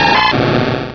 Cri de Crocrodil dans Pokémon Rubis et Saphir.